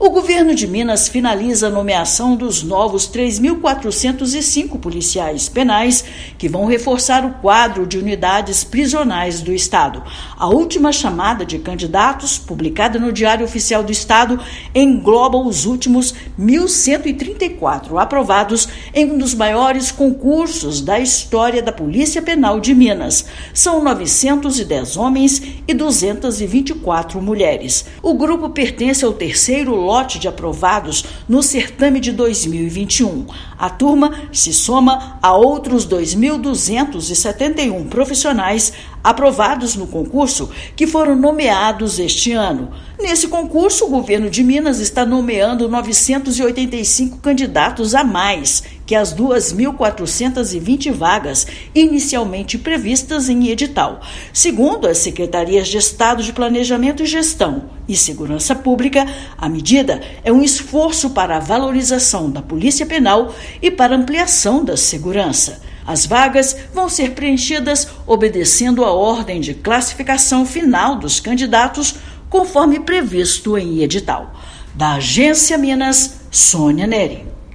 Última chamada de concurso, publicada nesta quinta-feira (6/6), completa 3.405 novos profissionais distribuídos no sistema prisional do estado. Ouça a matéria de rádio: